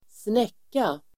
Uttal: [²sn'ek:a]